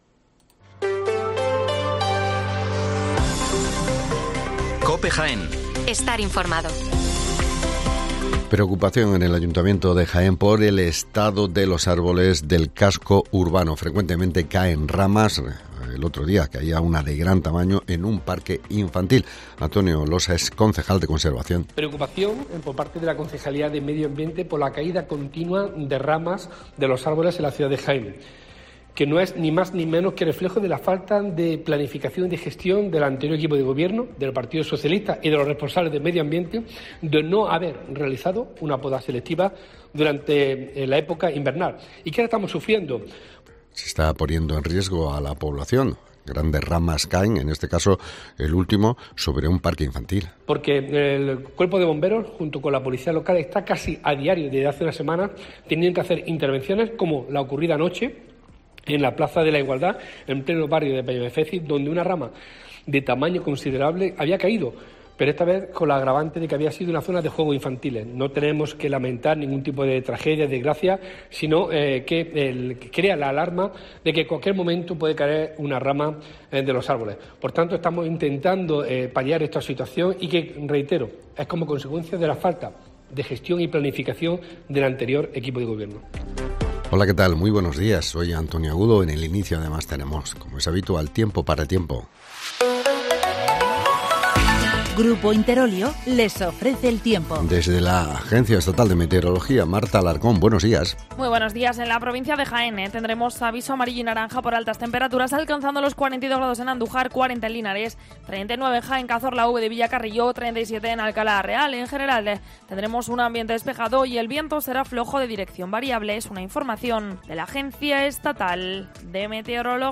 Las noticias locales de las 8'24 del 24 de agosto de 2023